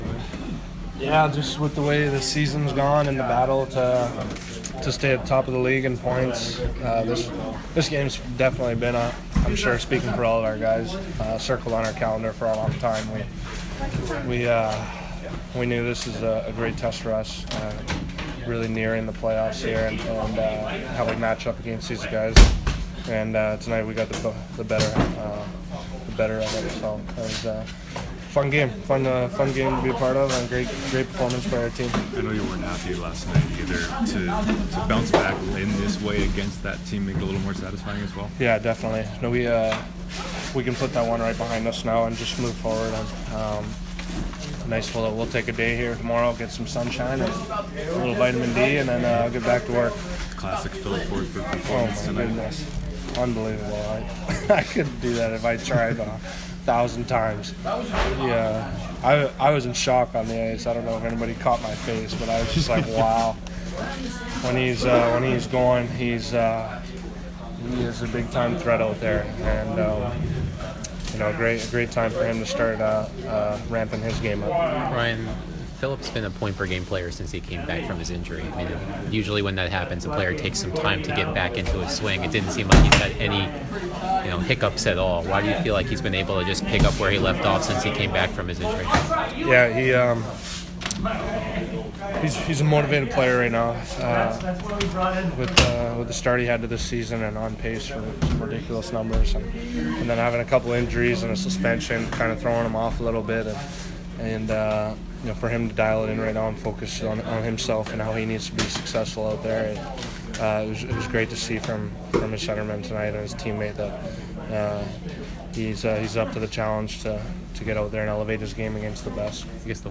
Ryan Johansen post-game 4/1